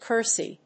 音節ker・sey 発音記号・読み方
/kˈɚːzi(米国英語), kˈəːzi(英国英語)/